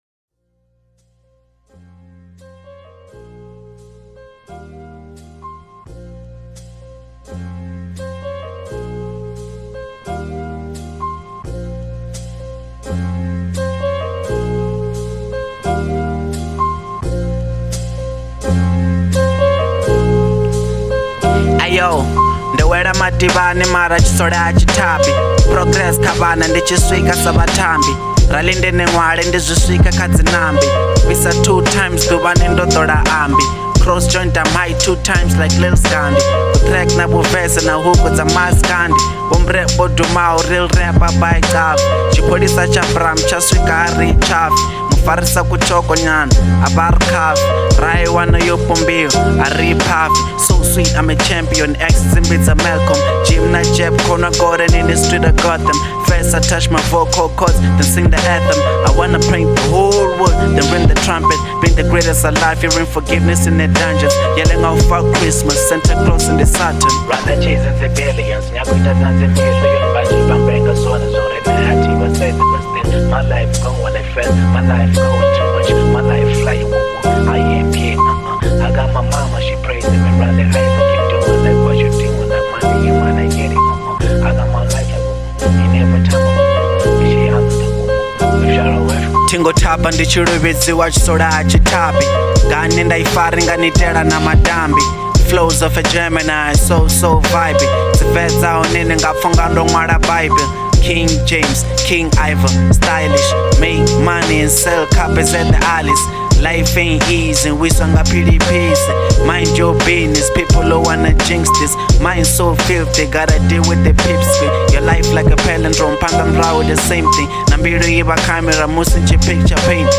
02:46 Genre : Venrap Size